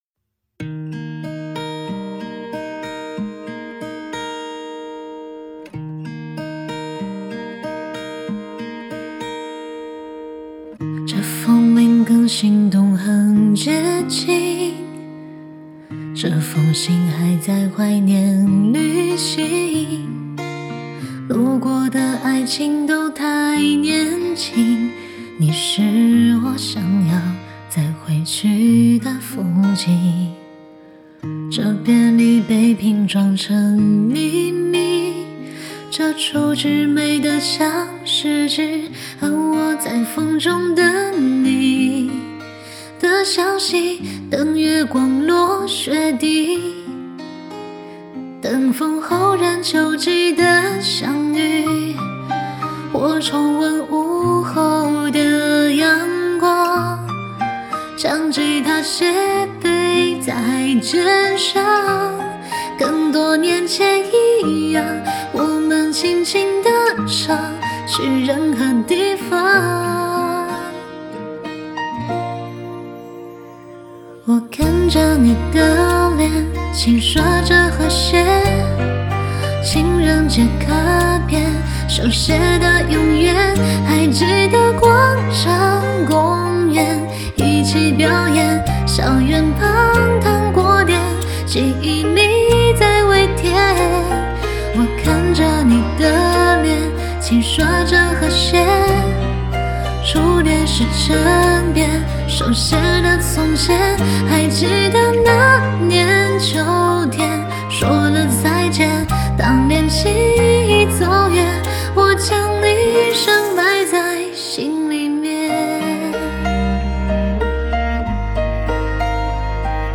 Ps：在线试听为压缩音质节选，体验无损音质请下载完整版 无歌词